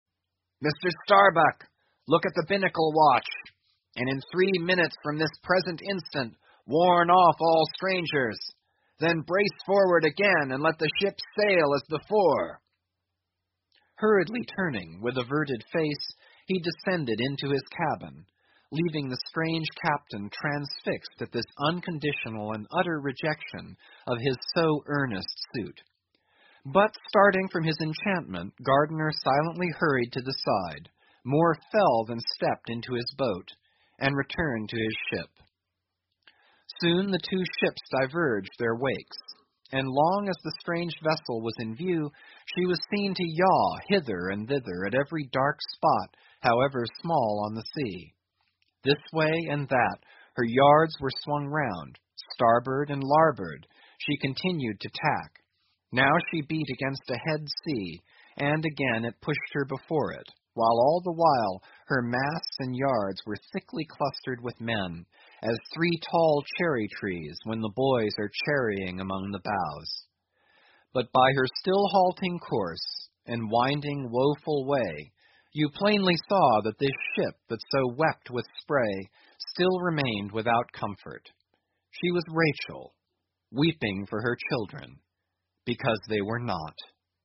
英语听书《白鲸记》第983期 听力文件下载—在线英语听力室